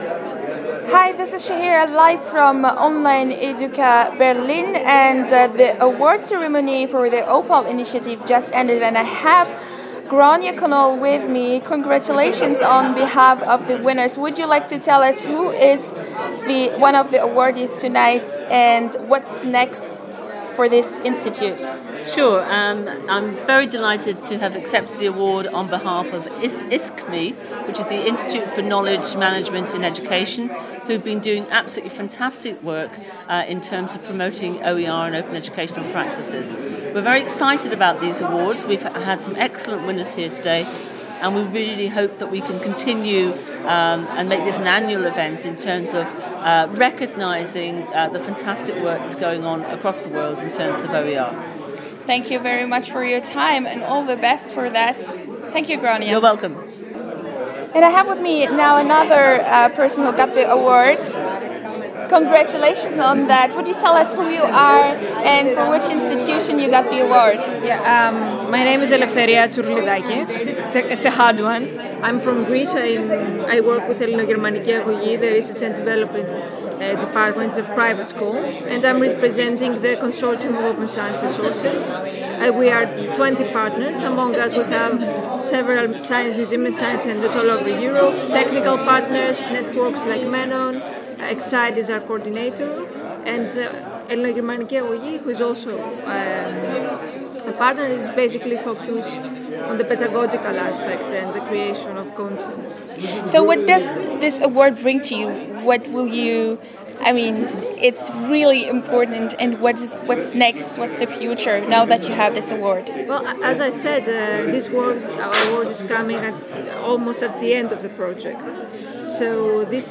Interviews with the OPAL Awards Winners 2011, Berlin (part I)